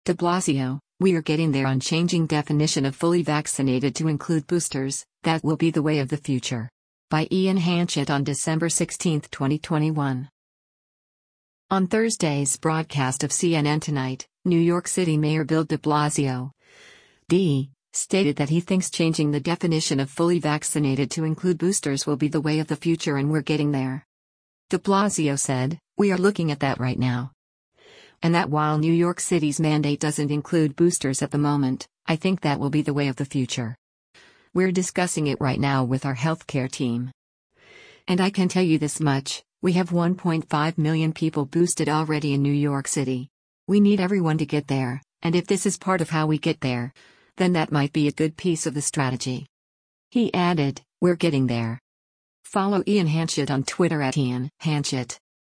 On Thursday’s broadcast of “CNN Tonight,” New York City Mayor Bill de Blasio (D) stated that he thinks changing the definition of “fully vaccinated” to include boosters “will be the way of the future” and “We’re getting there.”